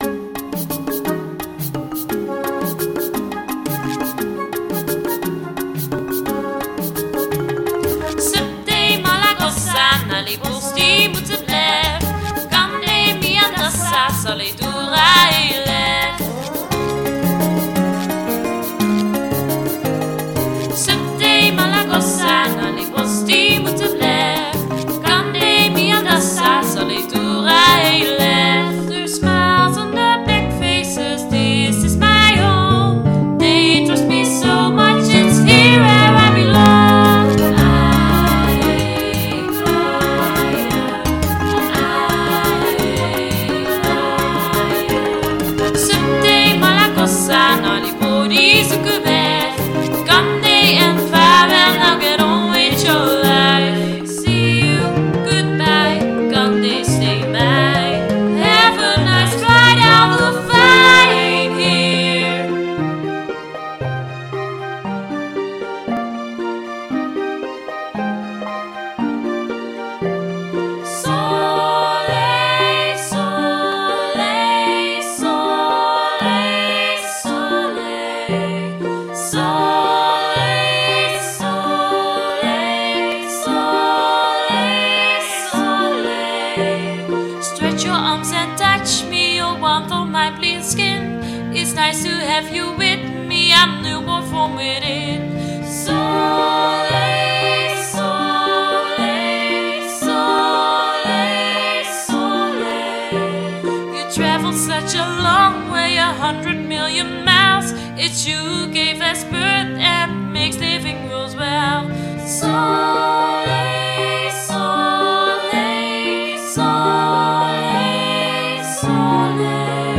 Een concept met meerdere zangstukjes is in ontwikkeling.